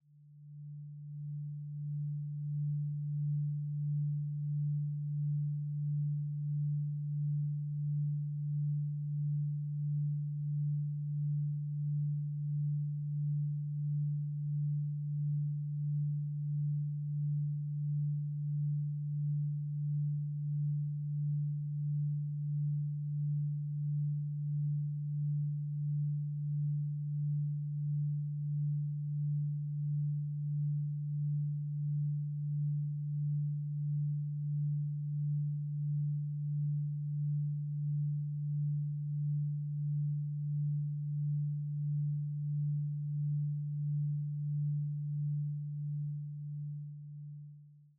Duration: 0:48 · Genre: Lo-Fi Hip Hop · 128kbps MP3